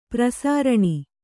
♪ prasāraṇi